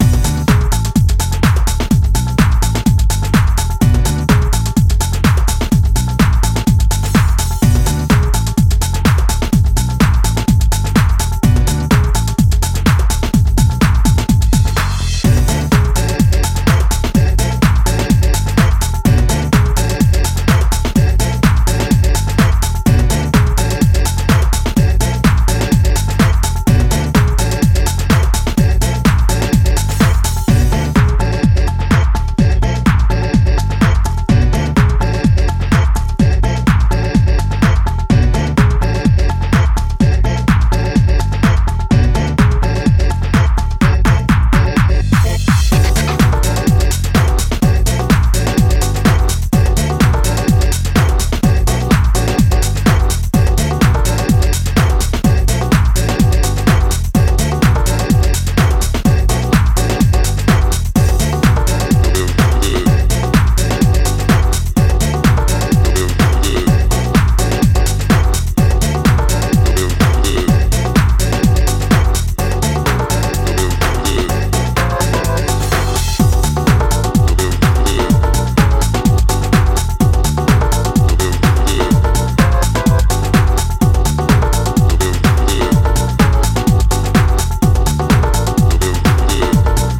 90’s 初頭のレイヴィーなムードをモダンに洗練させたハウスグルーヴ